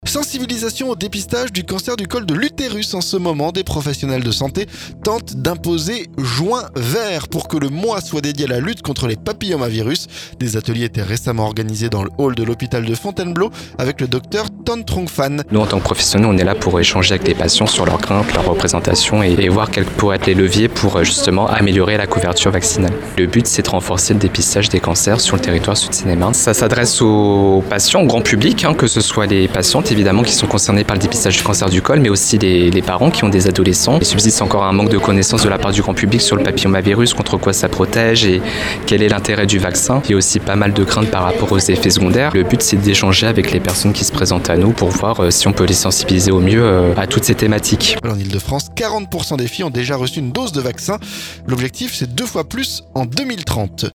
*Reportage